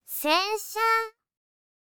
TTS comparison (Pitch Accent)
Cevio AI (Satou Sasara, CV: Minase Inori)
00_Sato-Sasara_洗車.wav